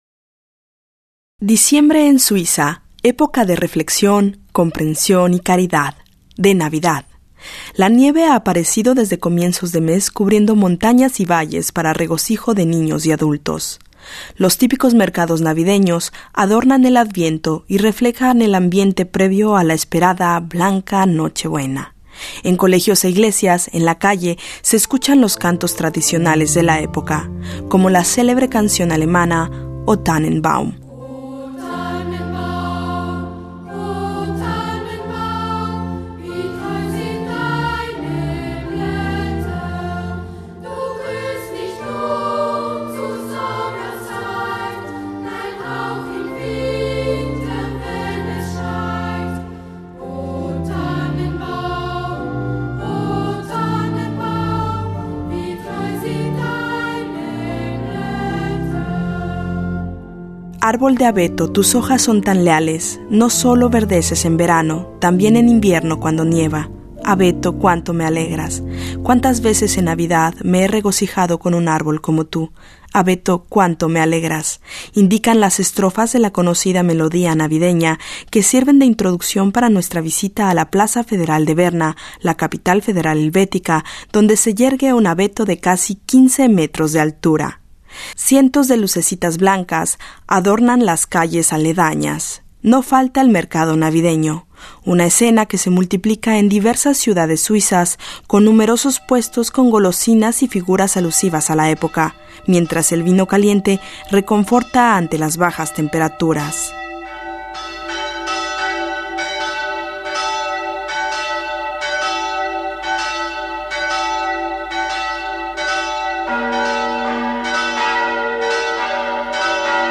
Un reportaje